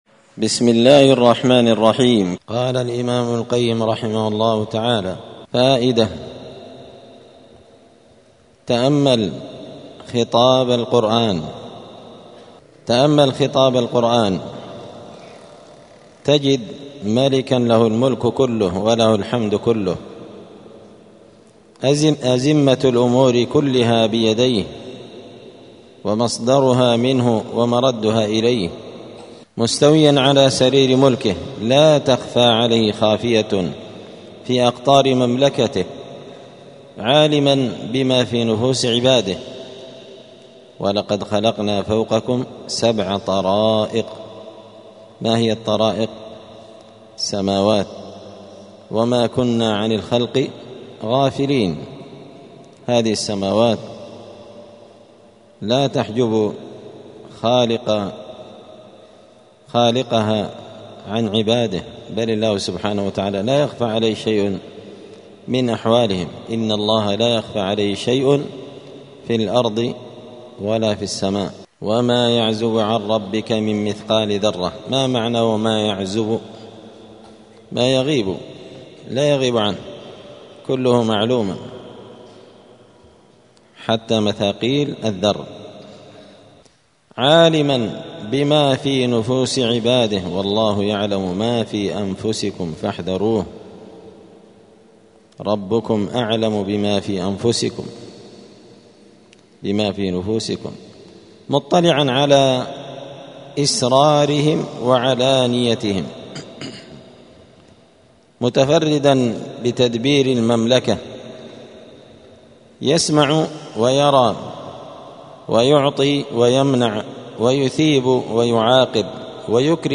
*الدرس الثاني عشر (12) (فصل: فائدة في أنزه الموجودات)*